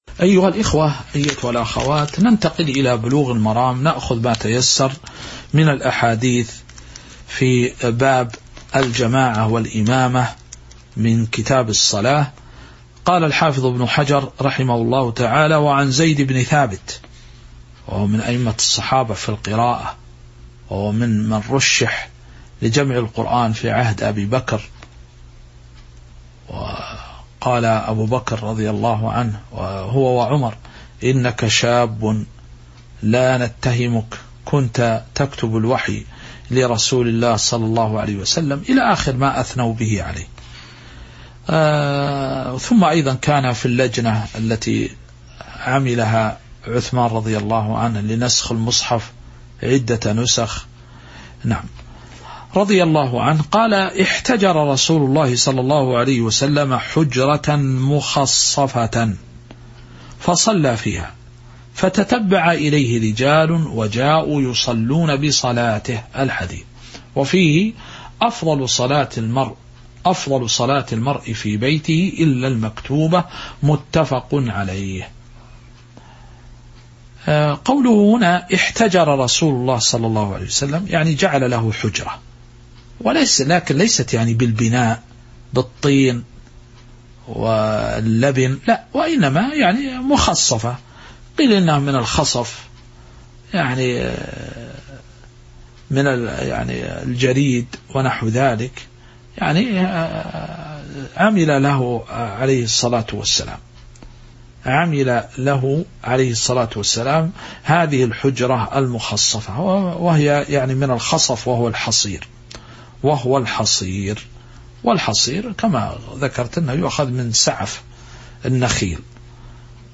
تاريخ النشر ٥ جمادى الآخرة ١٤٤٥ هـ المكان: المسجد النبوي الشيخ